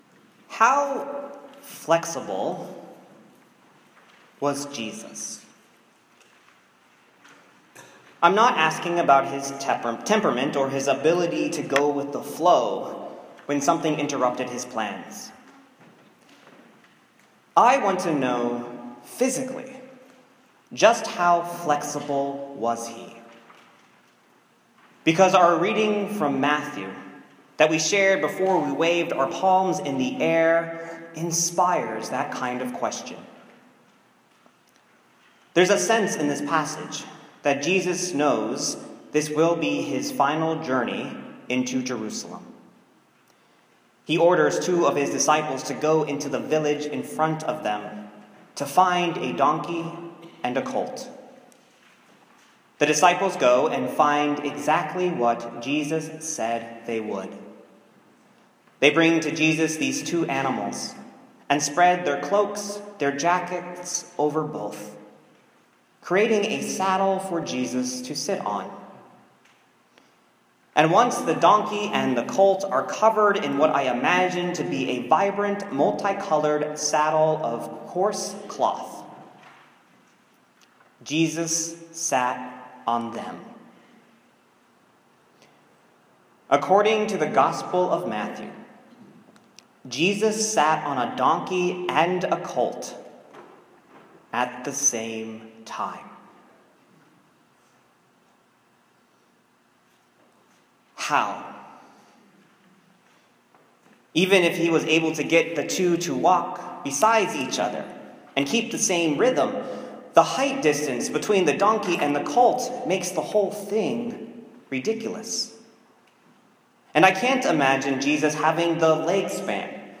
[sermon on 4.9.2017] [bulletin commentary 4.9.2017], [sermon on 4.5.2020], [sermon on 3.29.2026], [children’s sermon on 3.29.2026]